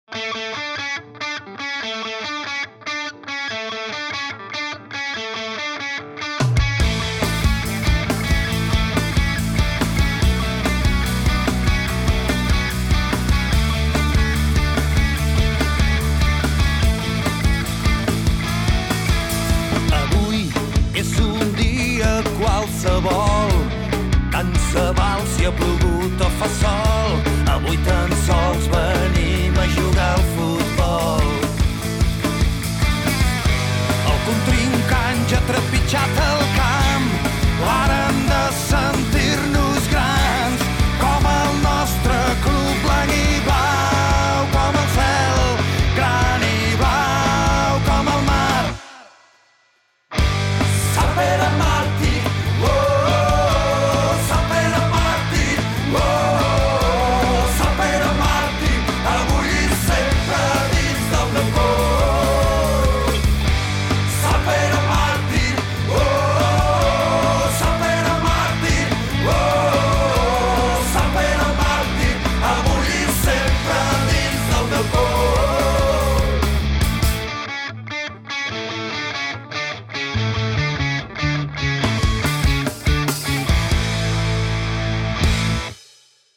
Creat i interpretat per: © PDP EXPERIENCE
himne.mp3